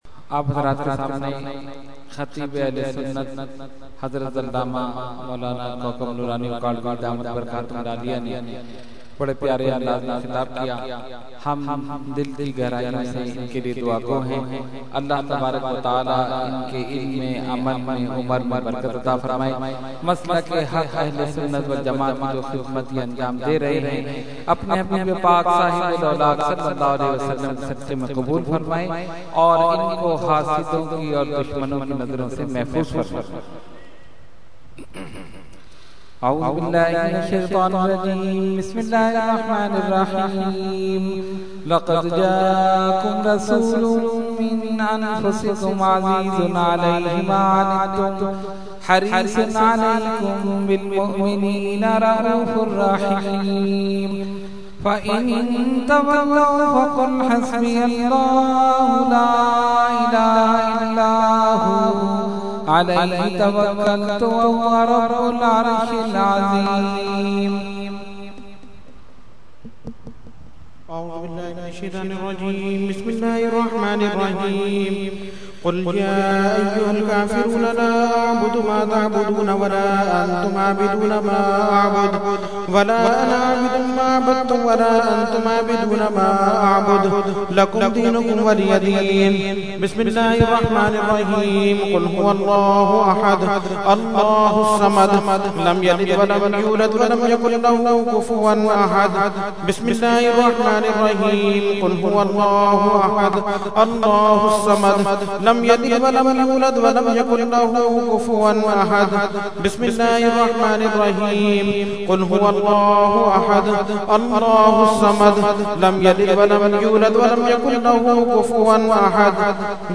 Fatiha Dua – Urs Qutbe Rabbani 2012 – Dargah Alia Ashrafia Karachi Pakistan
Category : Fatiha wa Dua | Language : ArabicEvent : Urs Qutbe Rabbani 2012